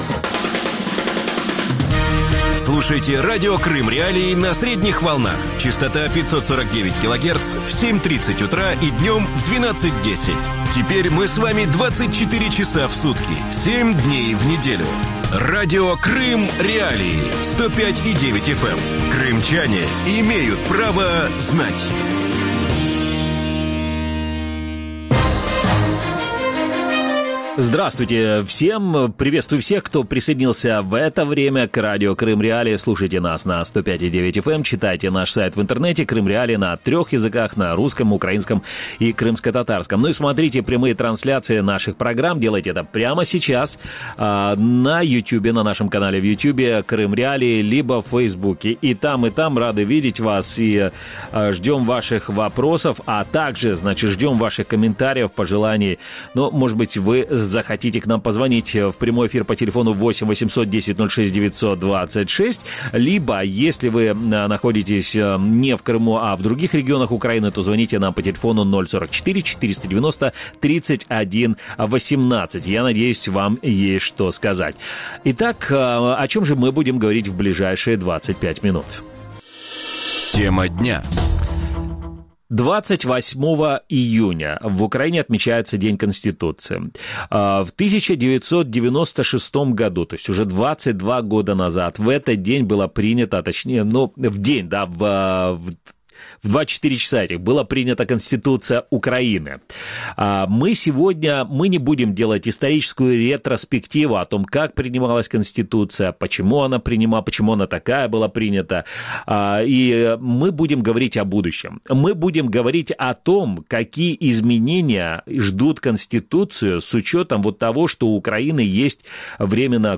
Какие изменения могут внести в Конституцию Украины в разделе, посвященном Крыму? Каковы перспективы у Крымскотатарской национальной автономии? Актуален ли главный свод законов Украины в современных реалиях? Гости эфира: Сергей Высоцкий, народный депутат Украины; Юрий Одарченко, народный депутат Украины;